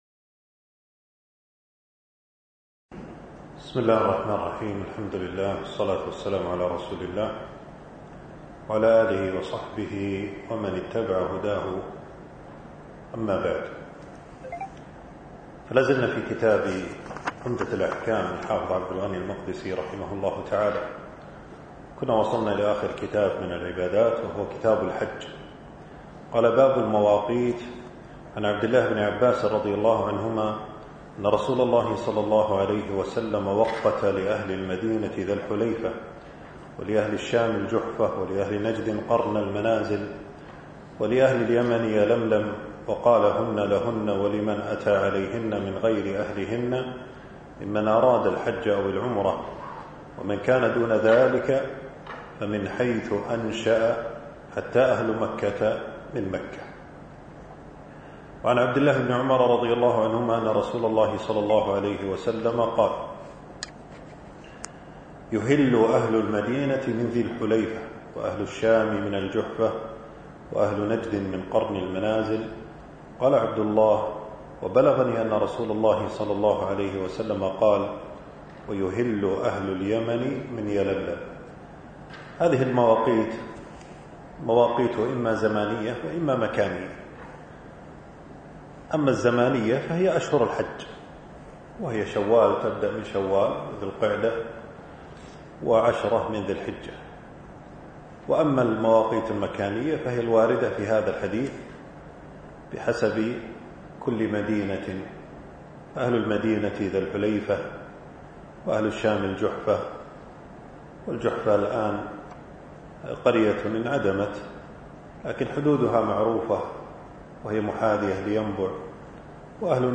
المكان: درس ألقاه في 6 جمادى الثاني 1447هـ في مبنى التدريب بوزارة الشؤون الإسلامية.